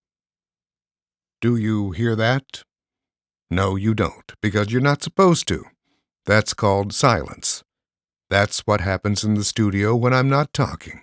Male
Adult (30-50), Older Sound (50+)
Believable, real, guy-next-door, gravitas, voice of God, friendly, quirky, serious, dramatic, funny, whimsical, magical, relatable, honest, sophisticated, sexy, ardent, warm, fuzzy, clever, professor, Sam Elliott, Berry White, extreme, normal, bizarre, business, narrator, Nat Geo narrator, Mike Rowe, evil, scary, mysterious, blue collar, forceful.
Studio Quality Sample
0325Studio_No_Noise.mp3